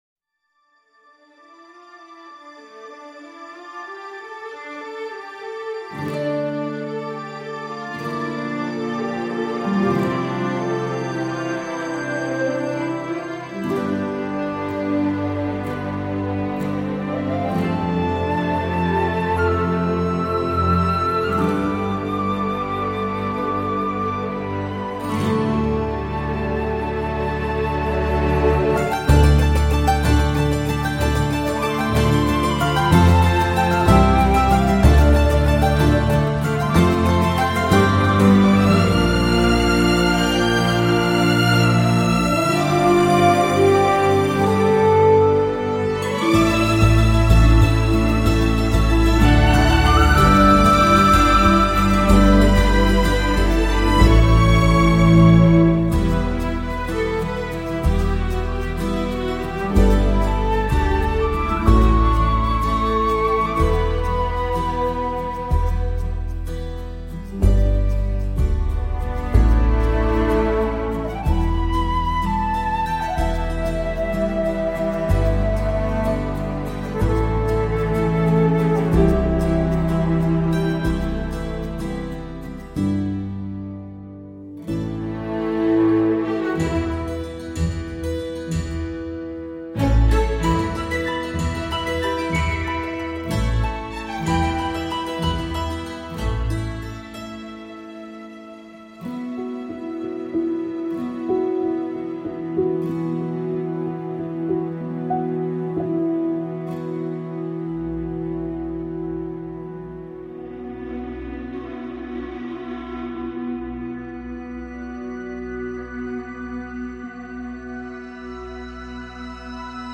piano, cordes et harpe